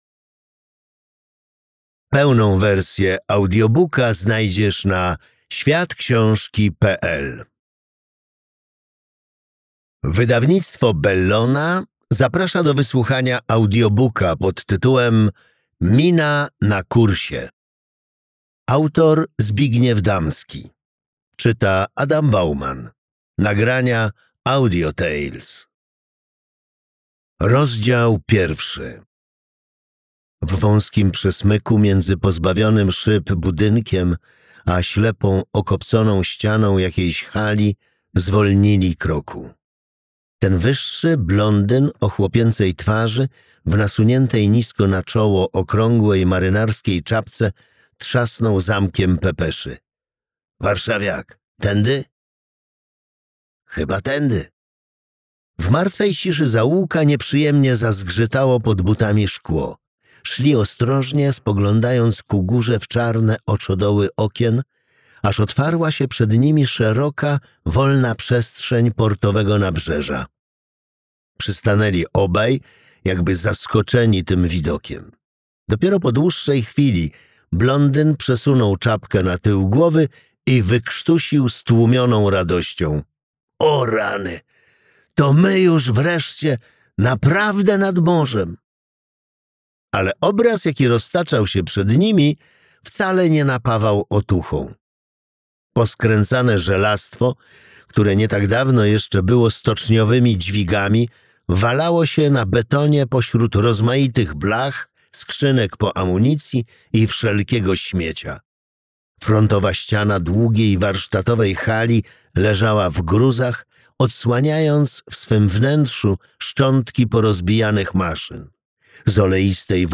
Mina na kursie - Damski Zbigniew - audiobook